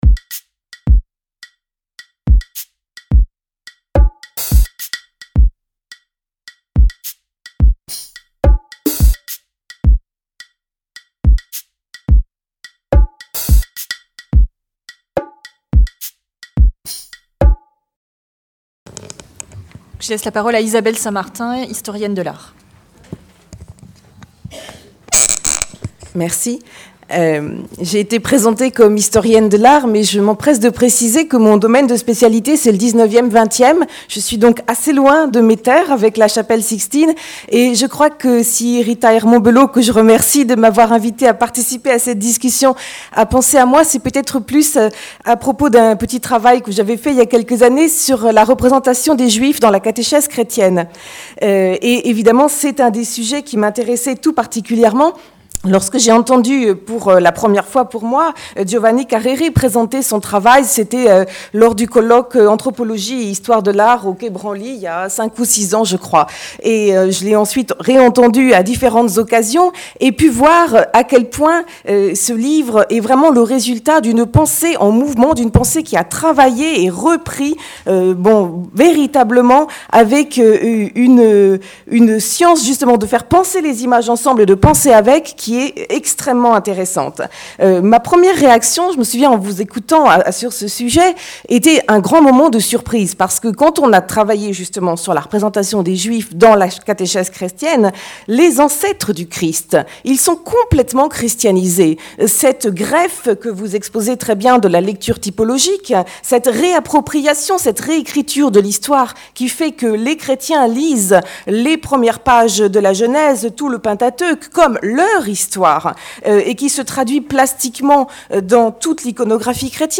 Conférence-débat organisée par les Cercles de formation de l'EHESS, les Éditions de l'EHESS, l’AFSR et le CEIFR.